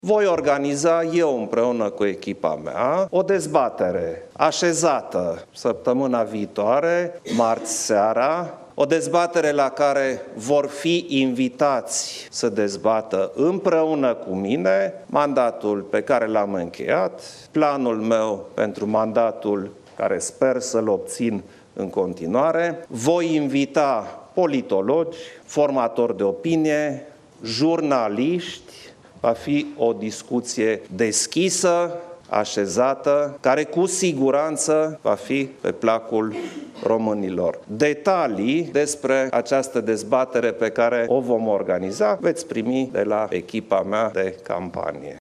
În această seară, preşedintele Klaus Iohannis a susţinut o conferinţă de presă, în timpul căreia s-a referit la primul tur al alegerilor prezidenţiale.